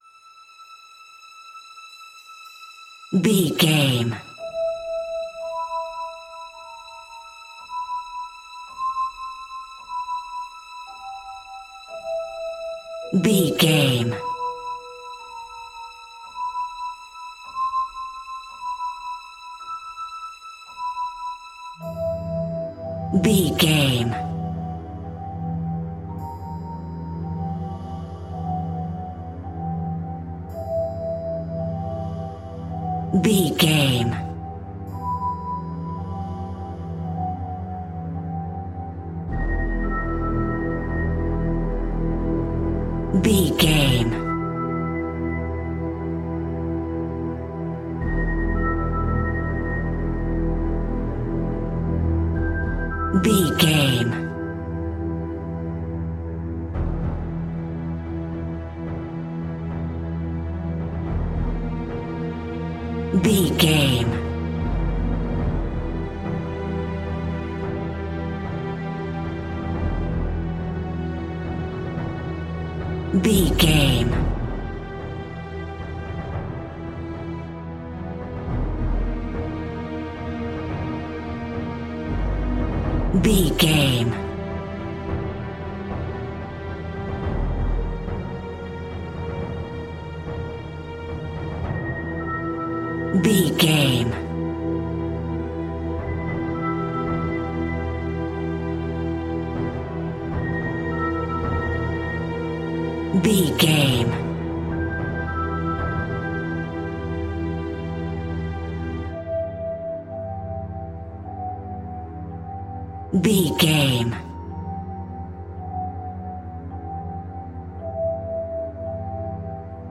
Aeolian/Minor
tension
ominous
dark
haunting
eerie
strings
percussion
mysterious
horror music
Horror Pads
horror piano
Horror Synths